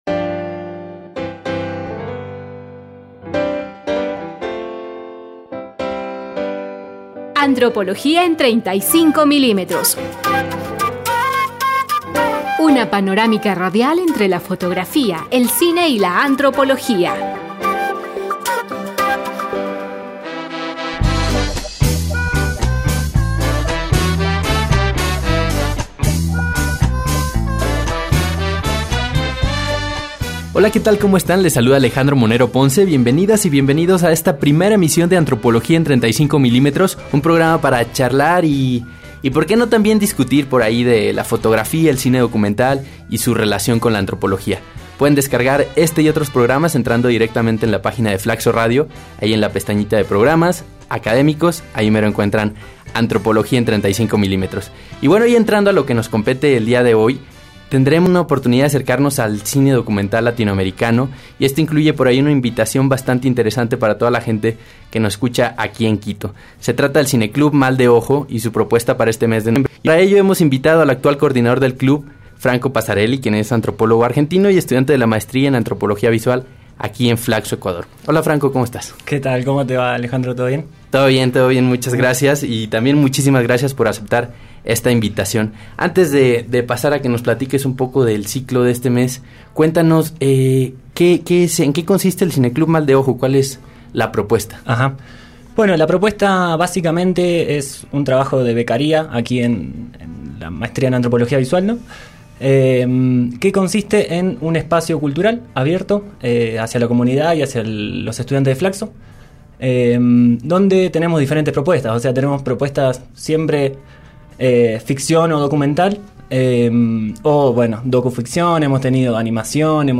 Antropología en 35 milímetros, un espacio para conversar sobre fotografía, cine documental y antropología